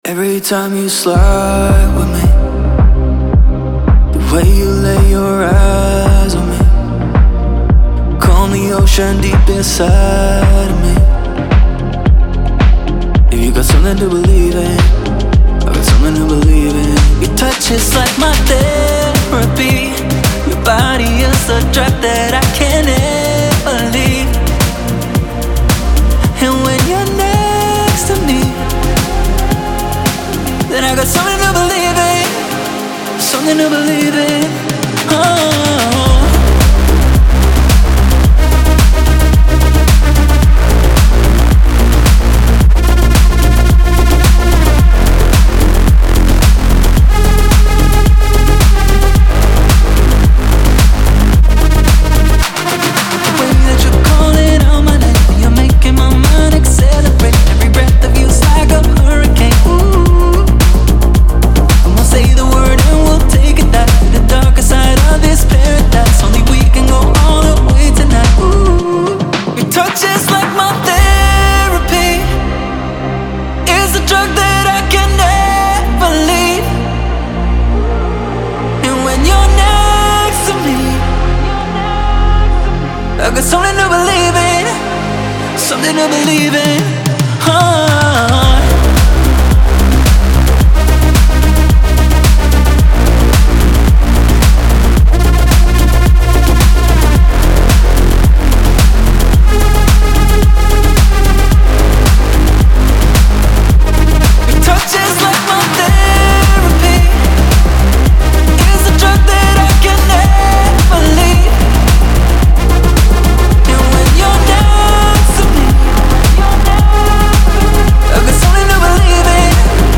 Жанр: Pop, Dance